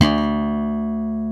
JS BASS #807.wav